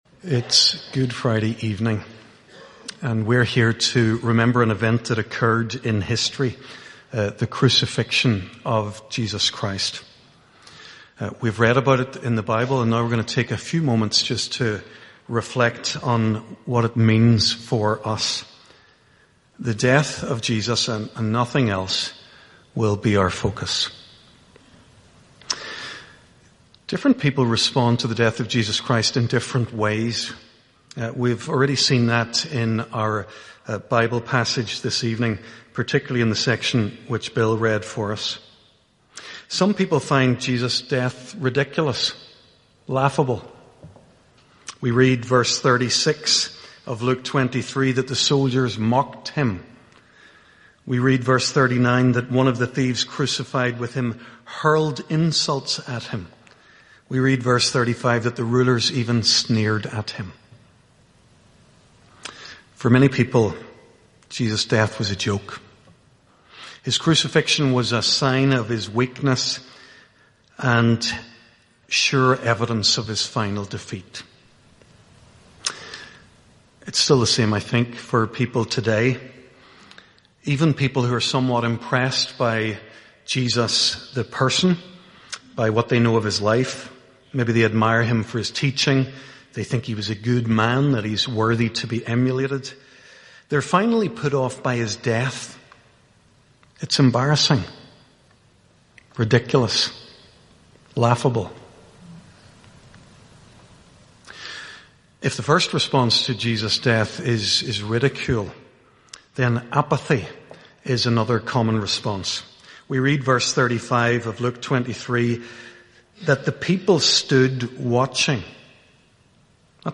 HRPC-Good-Friday-Service-Sermon-2026.mp3